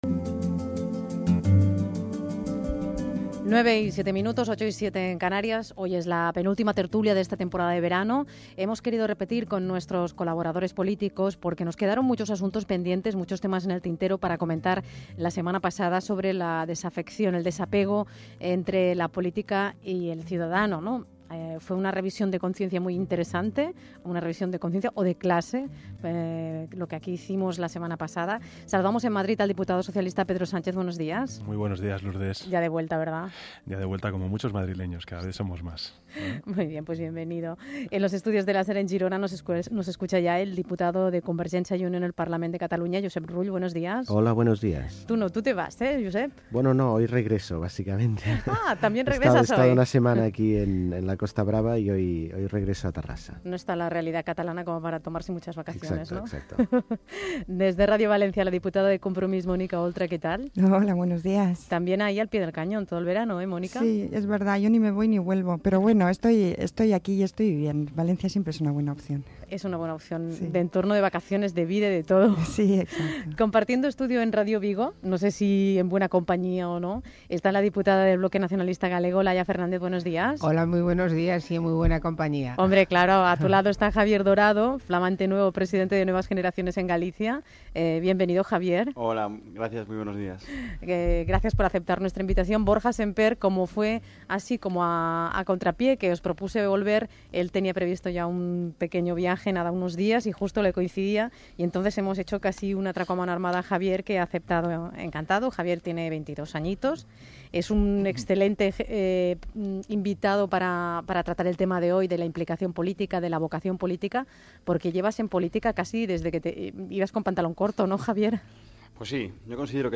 tertúlia política